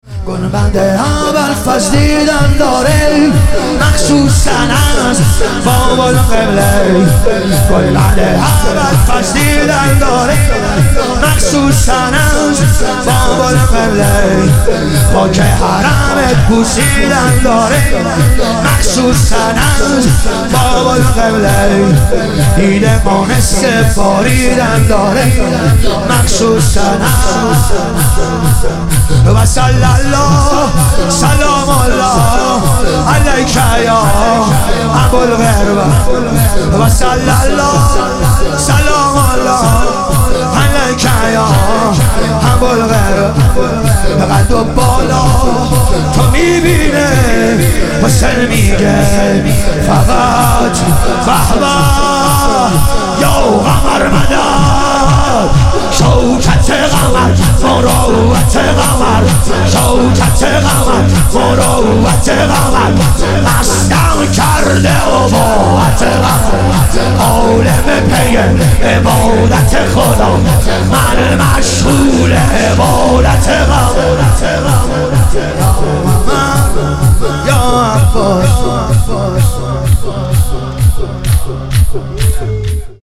شهادت امام حسن مجتبی علیه السلام - شور